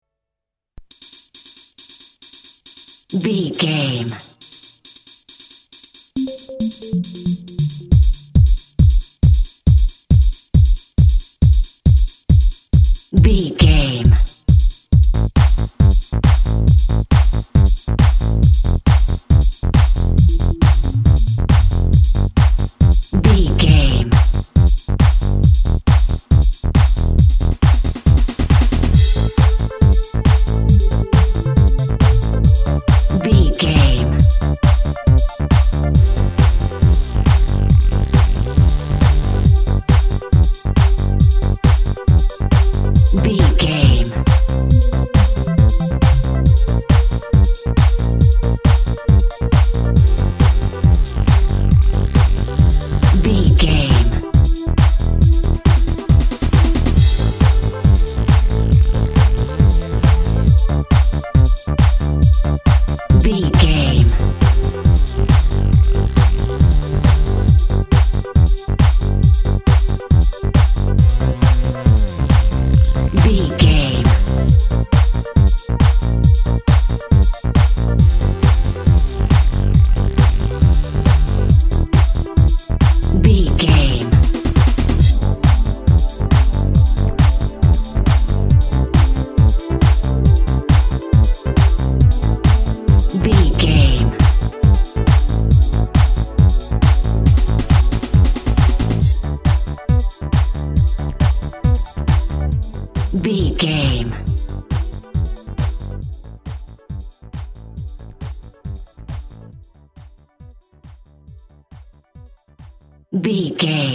Aeolian/Minor
Fast
groovy
uplifting
futuristic
energetic
cheerful/happy
funky
synthesiser
drum machine
techno
synth lead
synth bass
electronic drums
Synth Pads